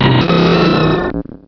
Cri de Braségali dans Pokémon Rubis et Saphir.
Cri_0257_RS.ogg